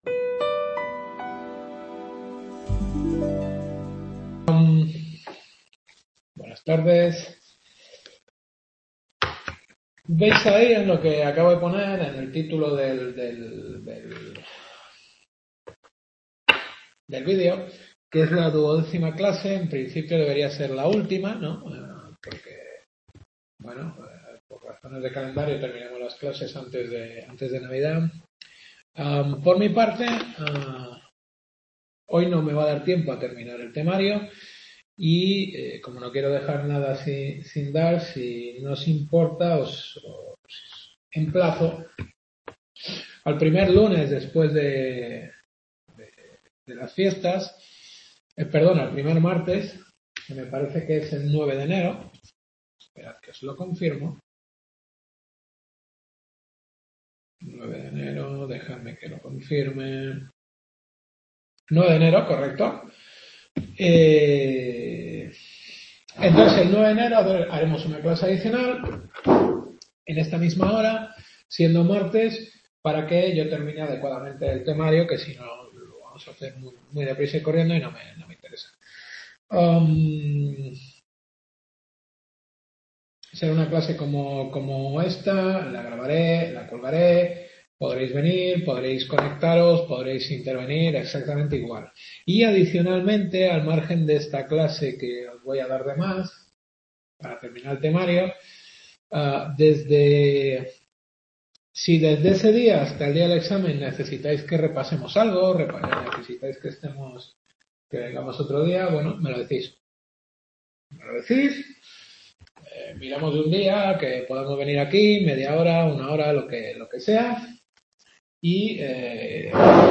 Duodécima Clase.